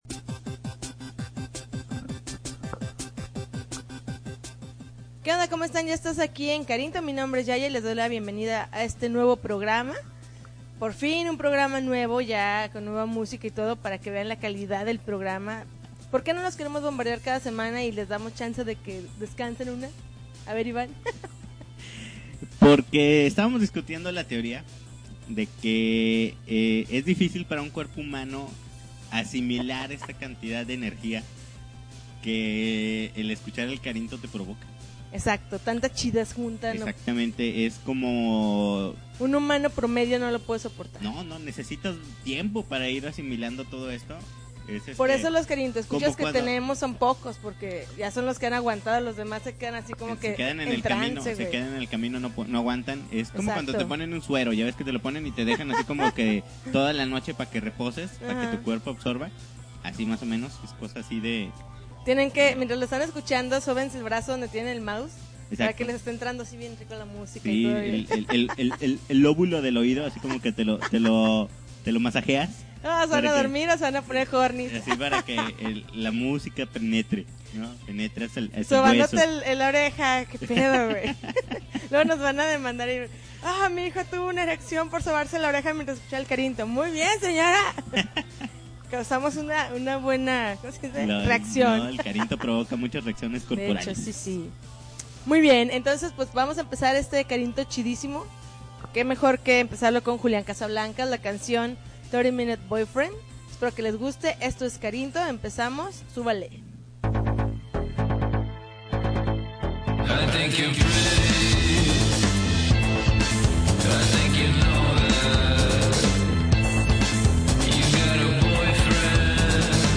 August 8, 2010Podcast, Punk Rock Alternativo